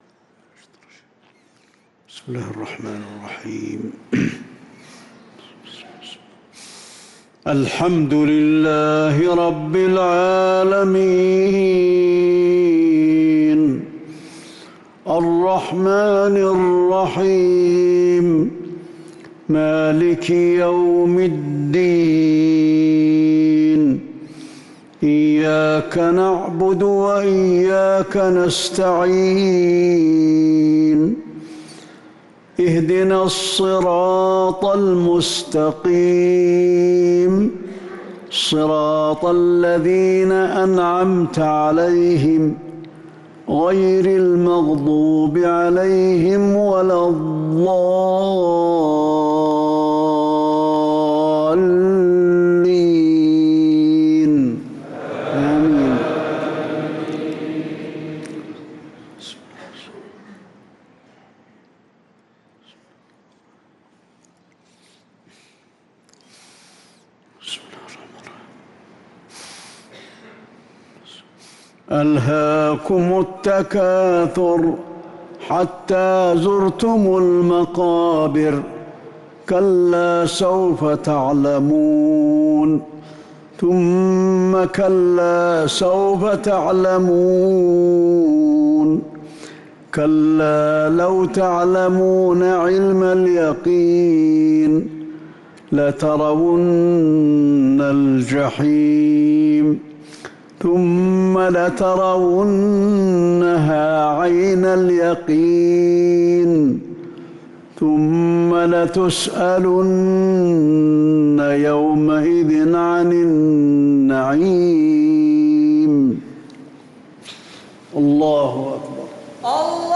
صلاة المغرب للقارئ علي الحذيفي 24 جمادي الأول 1445 هـ
تِلَاوَات الْحَرَمَيْن .